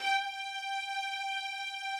strings_067.wav